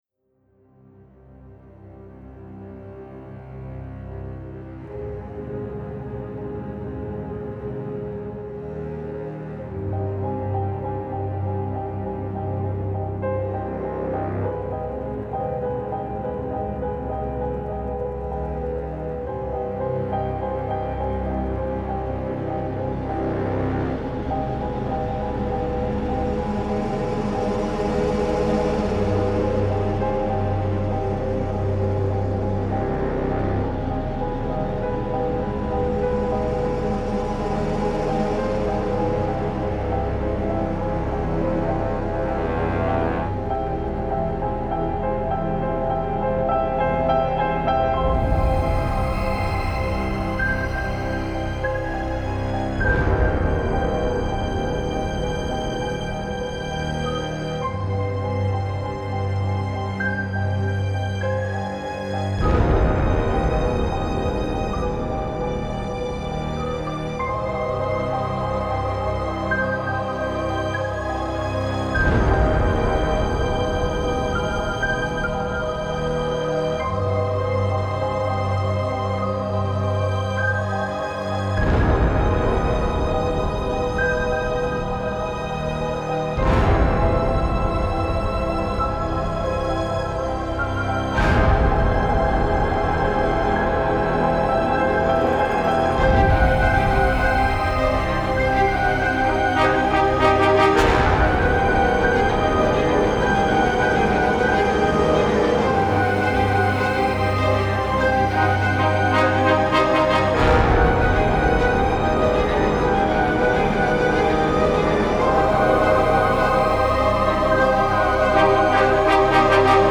Style Style Soundtrack
Mood Mood Dark, Epic, Intense +1 more
Featured Featured Brass, Choir, Flute +2 more
BPM BPM 100